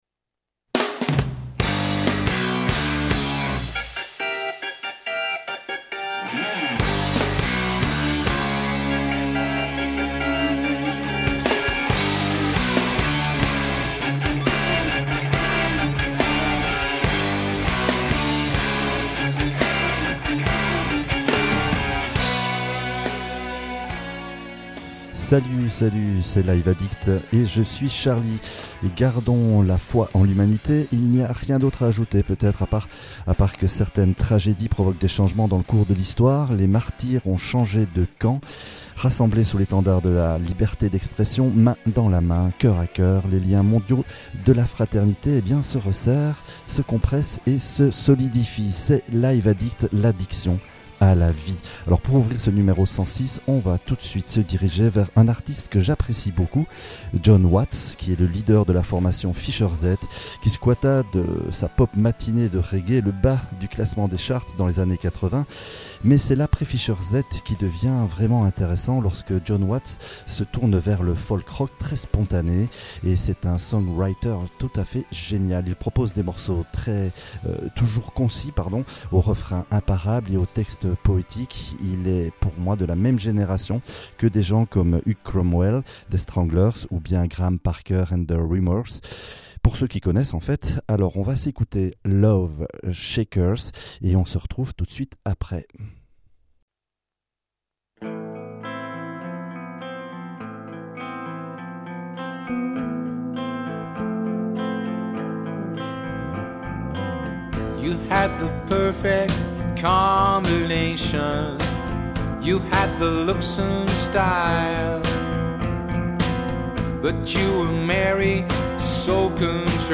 rock progressif